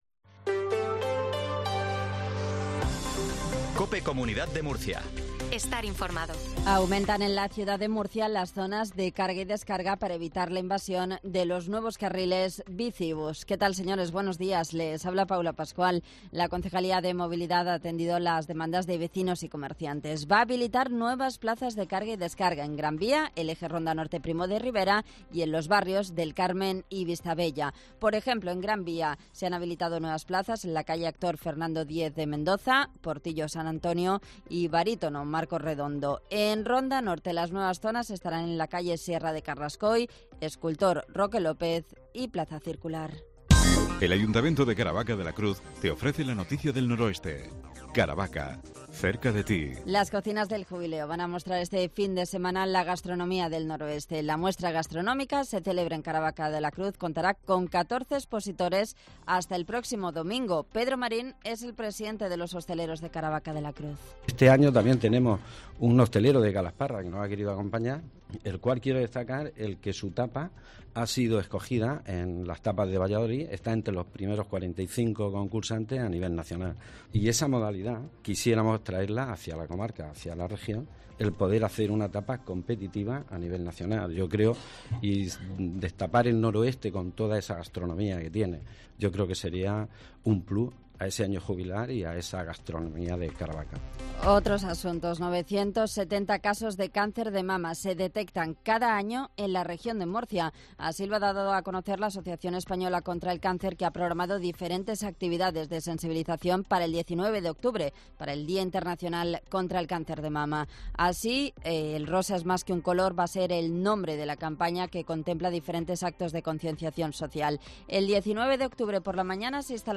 INFORMATIVO MATINAL REGION DE MURCIA 0820